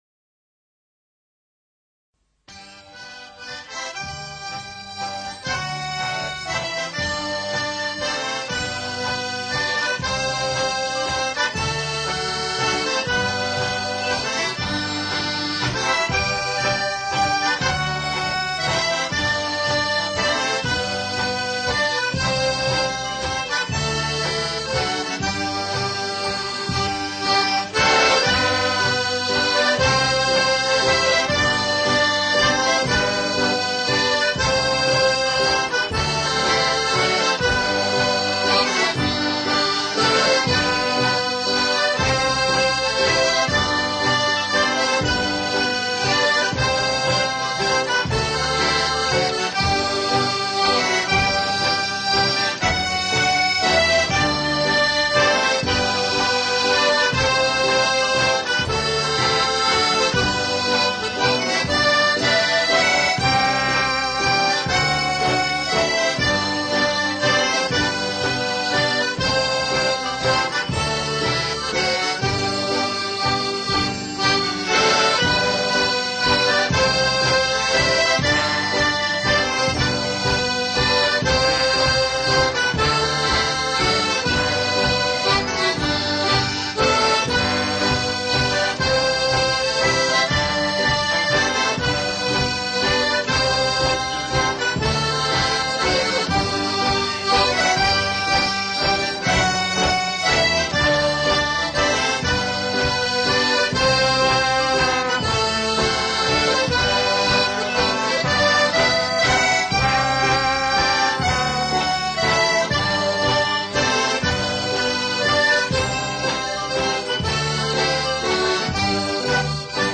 Long before The Hosepipe Band and Bass Instincts, there was The Hooligan Band, an unwieldy 11-piece ceilidh band which crept on the Essex dance scene in 1979 when we played for Castle Hedingham Folk Club Christmas Ceilidh.
Here are few typically raucous live recordings by a band ahead of its time.
Cajun-Waltz-1.mp3